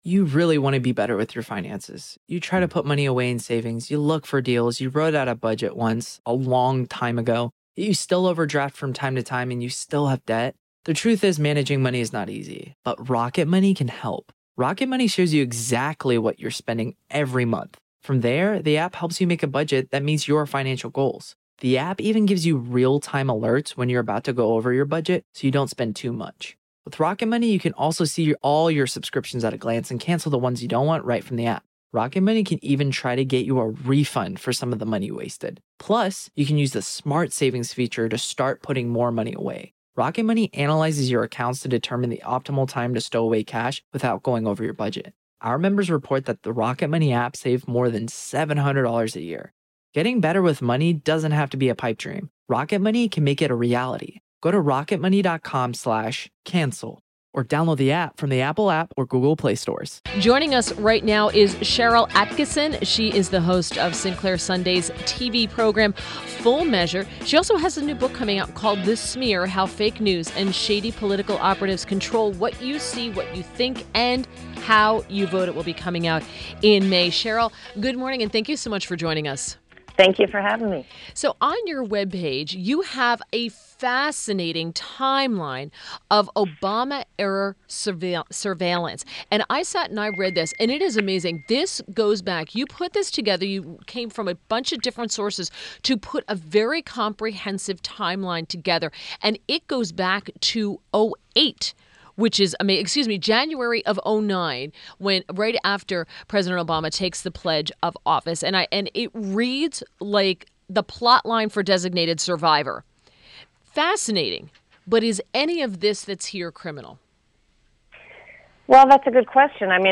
WMAL Interview - SHARYL ATTKISSON - 04.19.17